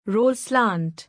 roll-slant.mp3